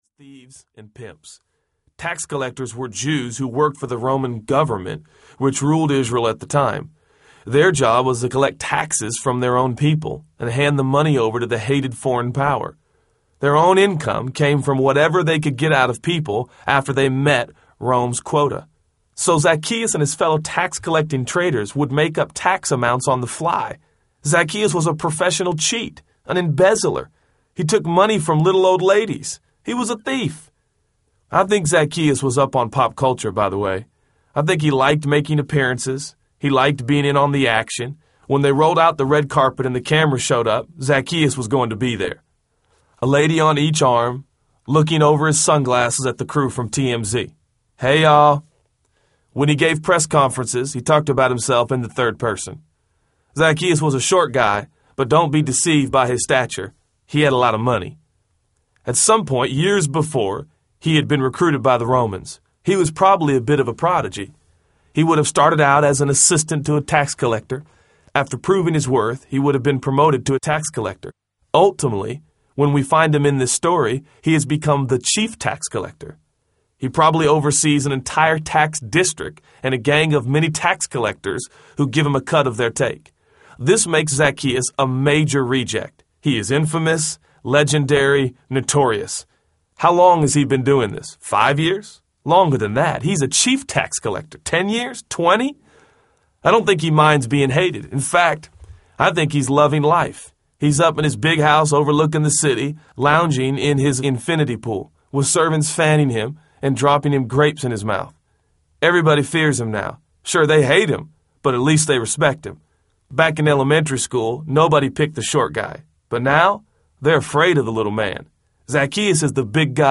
Jesus Is Audiobook
Narrator
– Unabridged